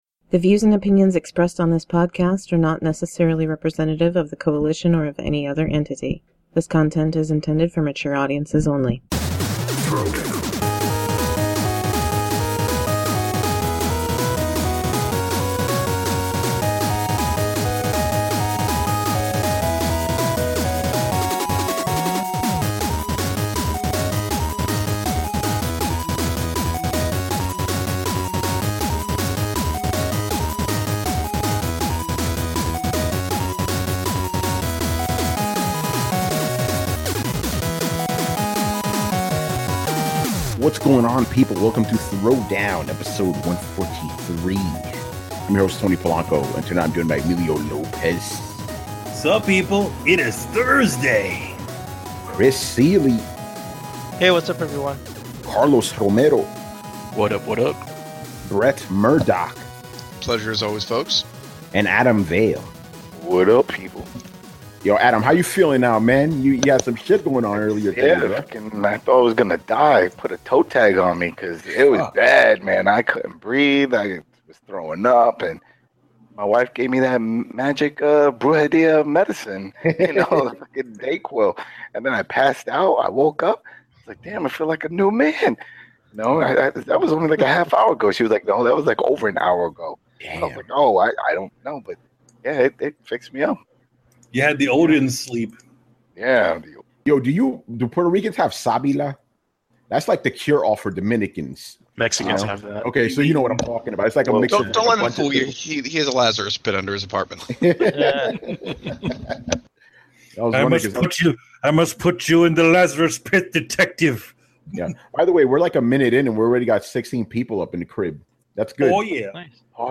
Follow the panelists on Twitter